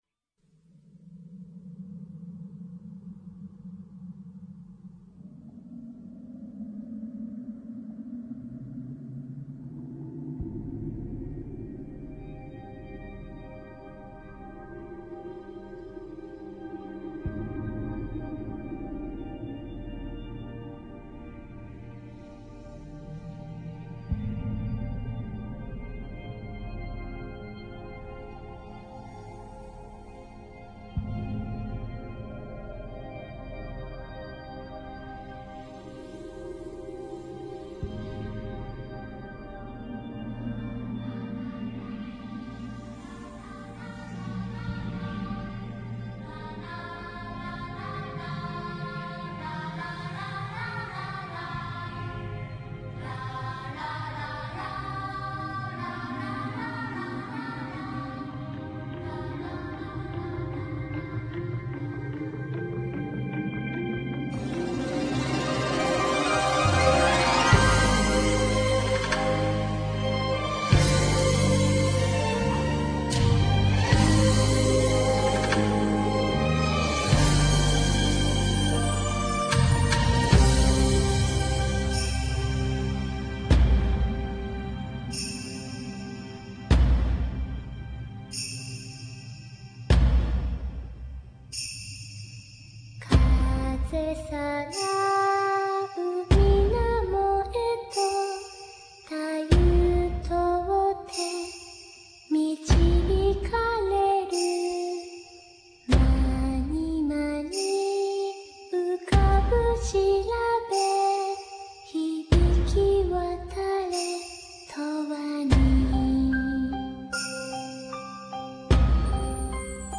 This is the version with vocals.